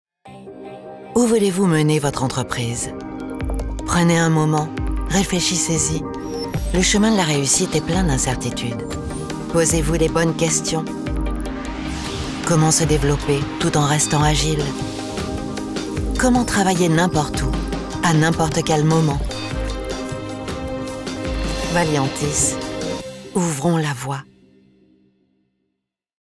Female
French (Parisienne)
Adult (30-50)